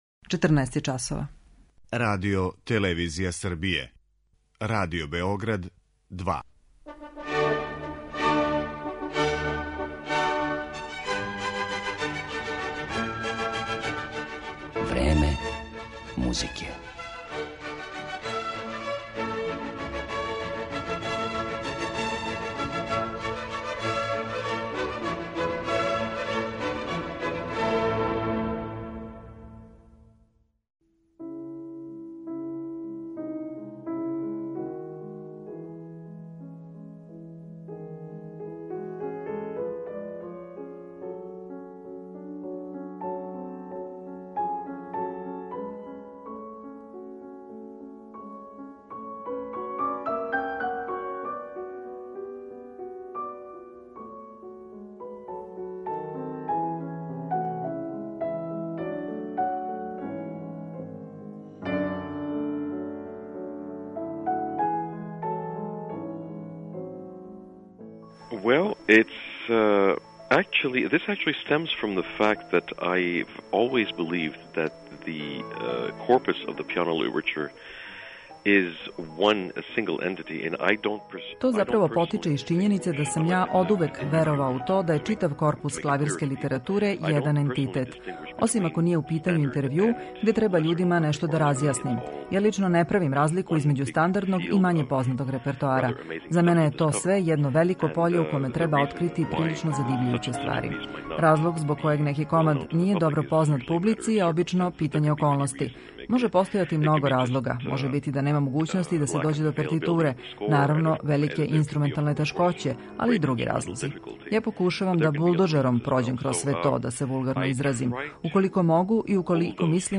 Канадски пијаниста Марк-Андре Амлен